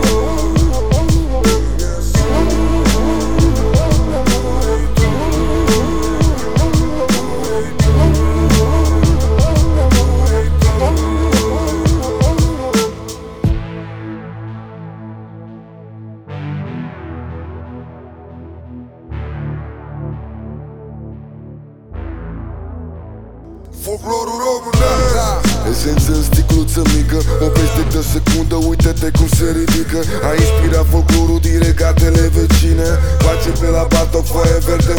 Жанр: Рэп и хип-хоп / Альтернатива
# Alternative Rap